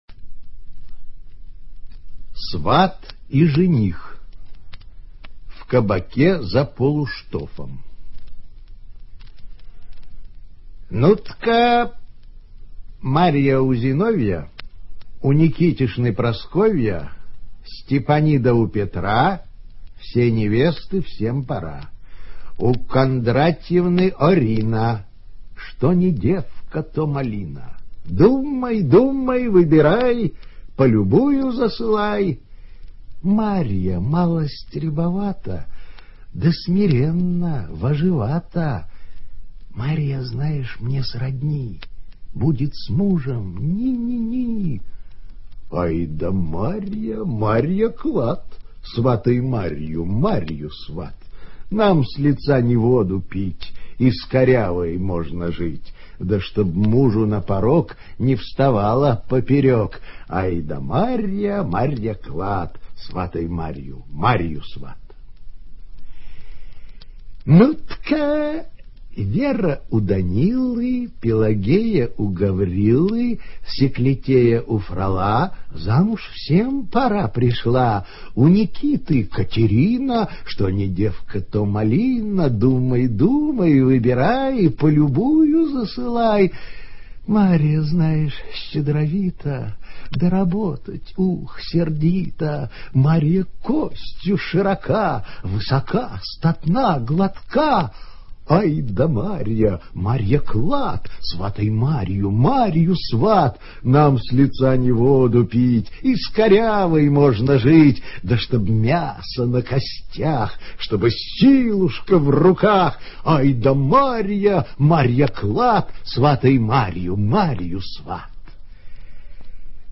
Прослушивание аудиозаписи песни «Сват и жених» с сайта «Старое радио». Исполнитель Я. Смоленский.